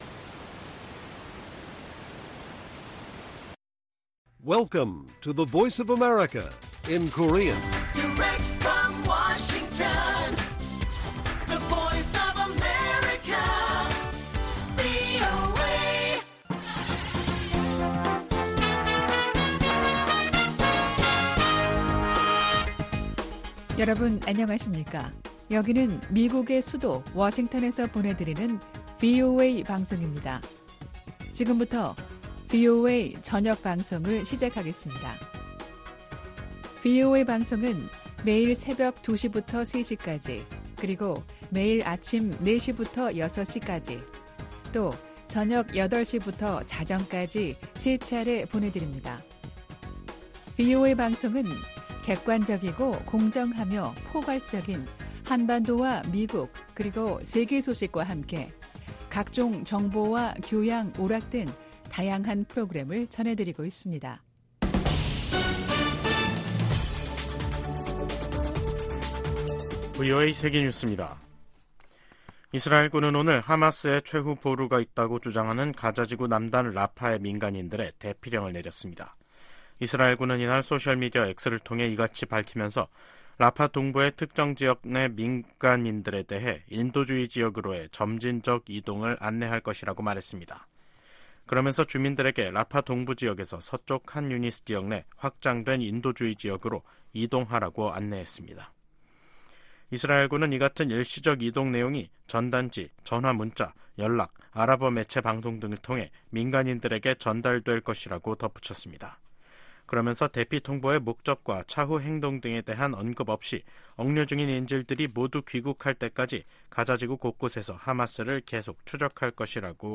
VOA 한국어 간판 뉴스 프로그램 '뉴스 투데이', 2024년 5월 6일 1부 방송입니다. 미국과 일본, 호주 국방장관들이 북러 군사협력 심화와 북한의 반복적인 미사일 발사를 강력히 규탄했습니다. 북한이 아무런 댓가없이 러시아에 무기를 지원하는 것은 아니라고 미국 관리가 말했습니다. 북러 무기 거래가 명백한 유엔 안보리 결의 위반이라는 점도 지적했습니다.